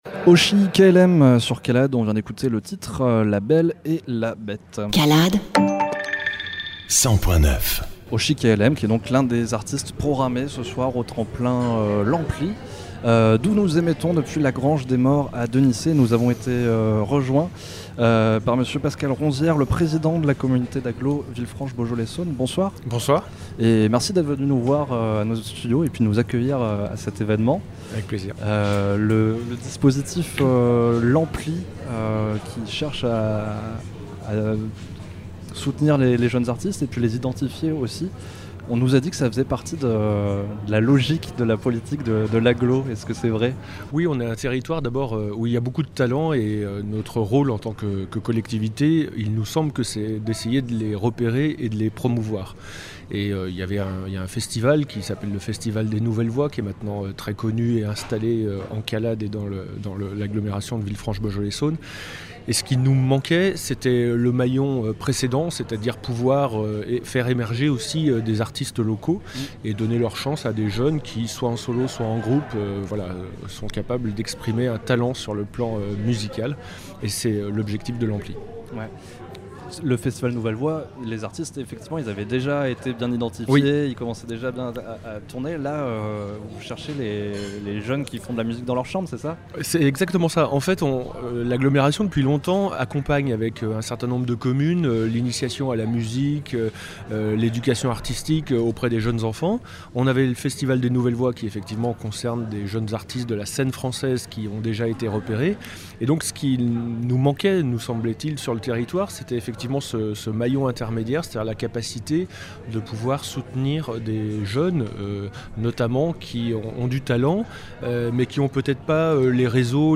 Ampli Interview